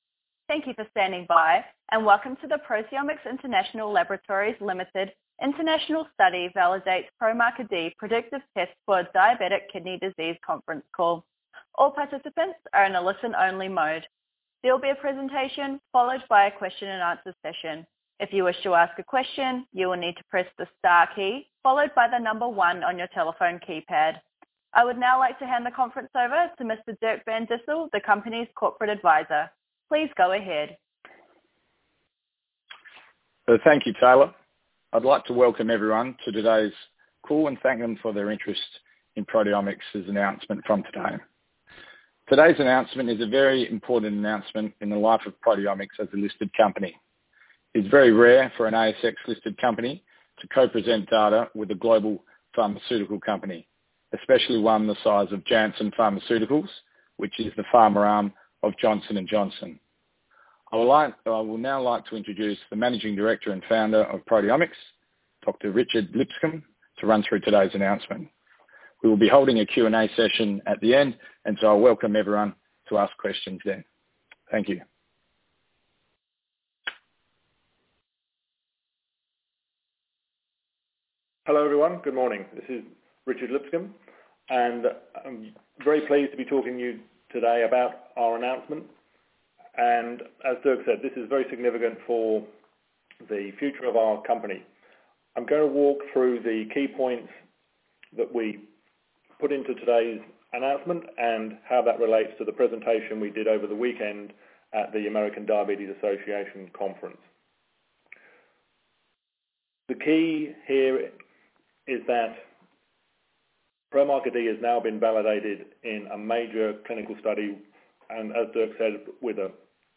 International Study Validates PromarkerD Predictive Test for Diabetic Kidney Disease – Investor Teleconference
Investor-Teleconference-International-Study-Validates-PromarkerD.mp3